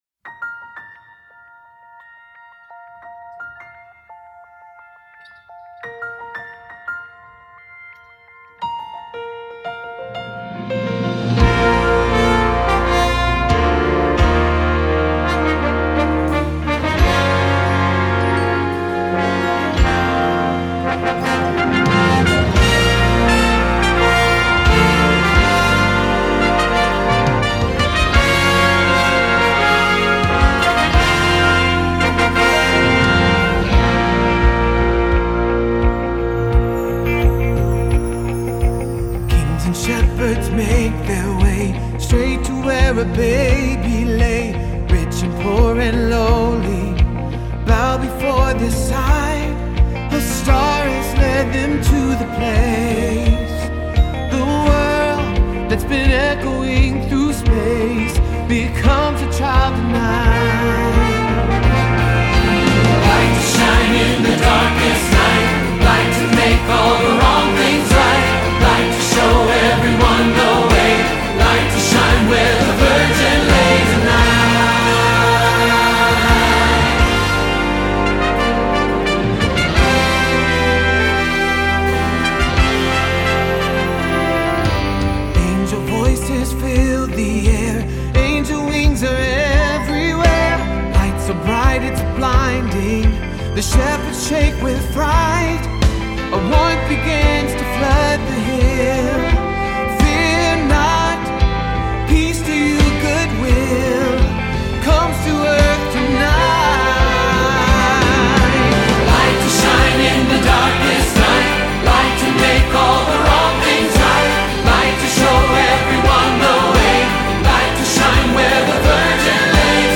Tonight (Choir, Ensemble, Accompaniment track)